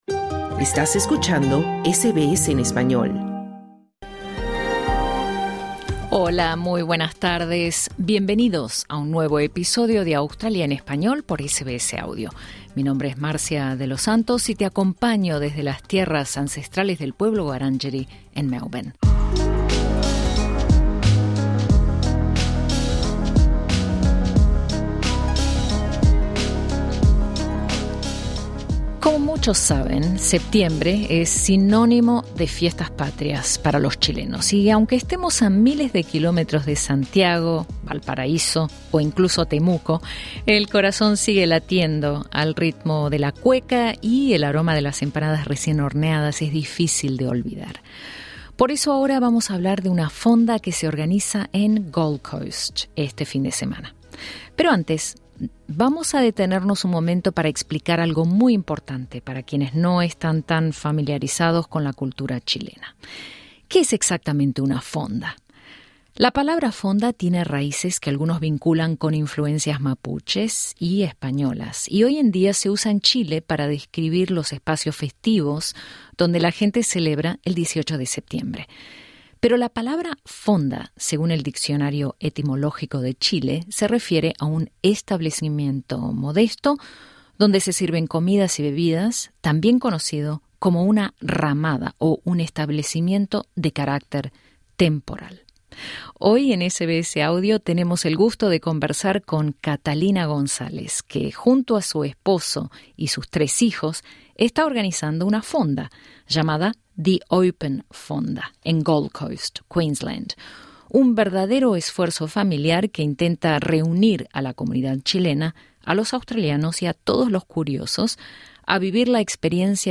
Escucha la entrevista completa pulsando el ícono de reproducción que se encuentra al inicio de la página.